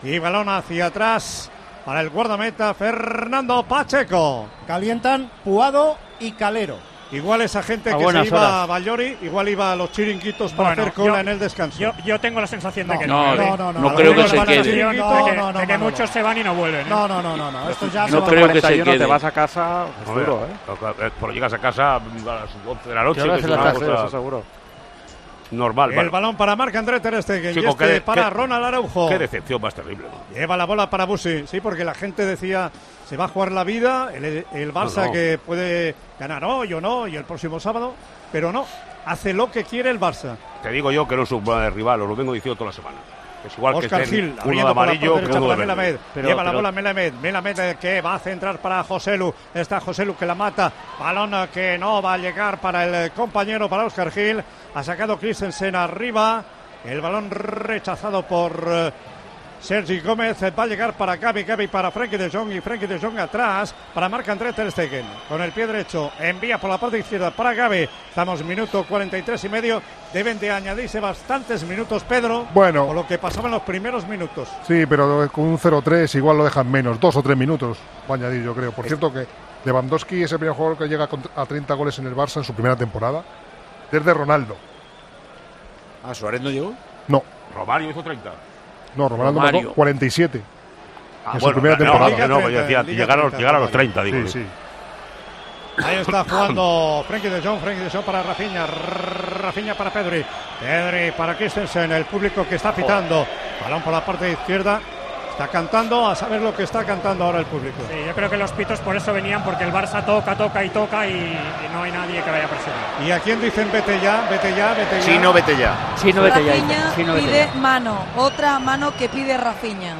La afición del Espanyol estalla contra la directiva a grito de "¡Chino, vete ya!"